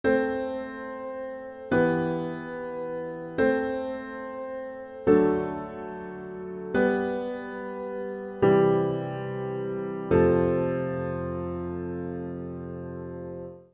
This is the piano part of variation 7 of Rachmaninoff’s Rhapsody on a theme of Paganini.